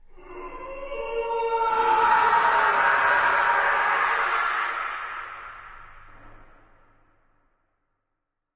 描述：A very scary scream, for use for stuff like scary movies and stuff. Unfortunately the sound came out distorted and so I have both the original dry distorted scream and a wet reverbed scream as well.
标签： castle dying haunted jail monster movie scary scream screaming
声道立体声